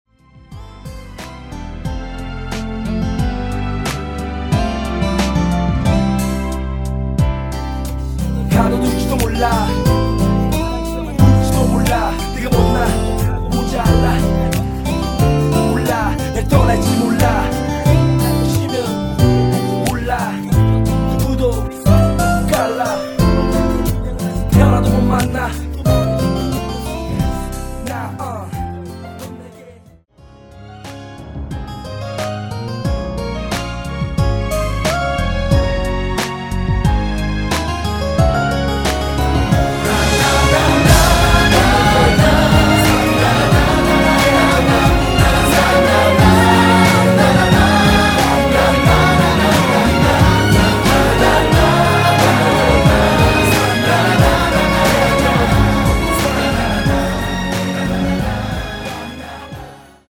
코러스 포함된 MR 입니다.
마지막 합창 부분이 코러스로 들어가 있습니다.
앞부분30초, 뒷부분30초씩 편집해서 올려 드리고 있습니다.
중간에 음이 끈어지고 다시 나오는 이유는